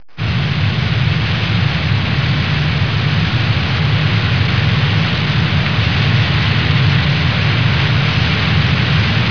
دانلود آهنگ طیاره 65 از افکت صوتی حمل و نقل
دانلود صدای طیاره 65 از ساعد نیوز با لینک مستقیم و کیفیت بالا
جلوه های صوتی